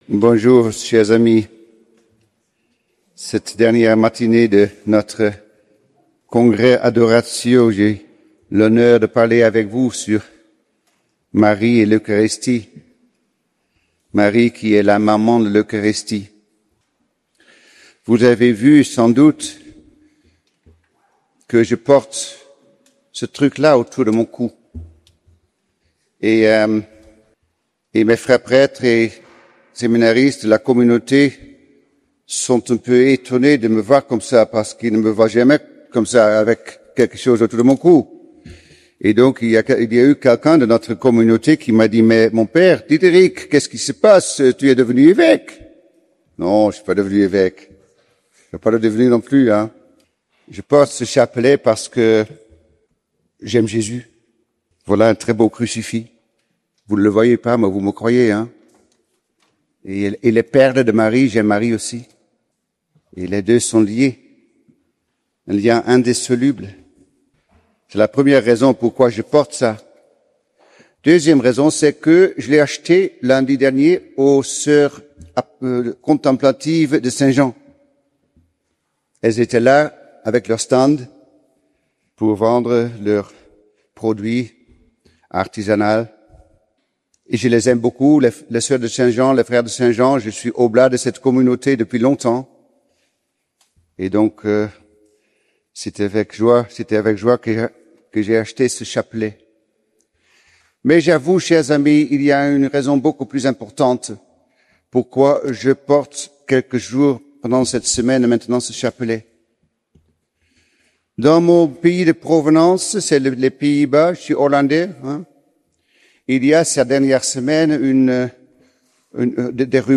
ND Laus, Congrès Adoratio